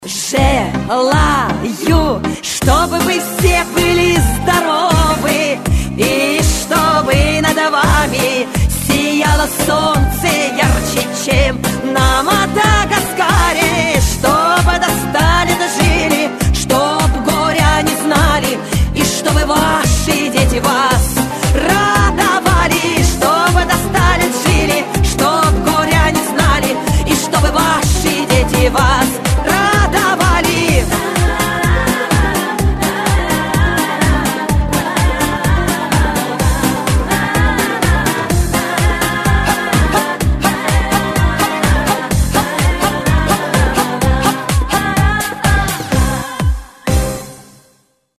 Танцевальные рингтоны
Поп